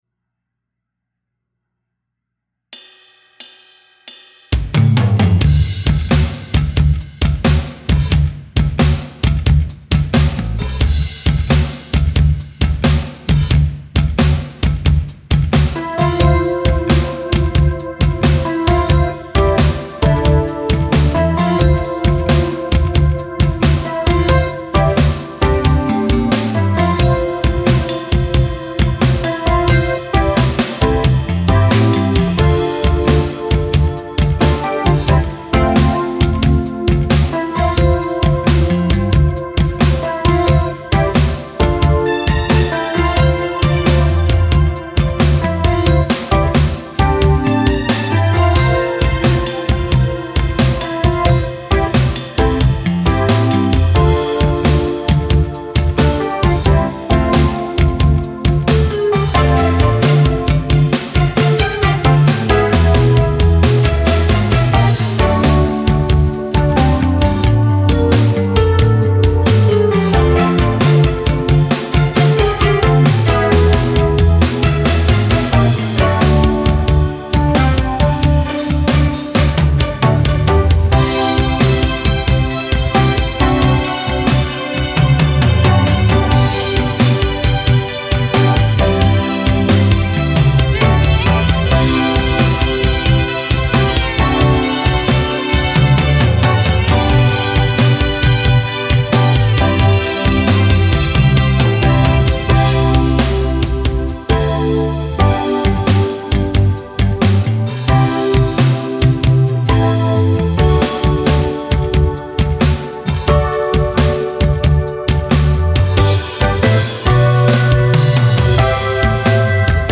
So, hier mal noch ein Blues von mir, den ich schon mal in einem anderen Thread hochgeladen hatte. Nochmal neu jemischt und ohne jeglichen Reverb.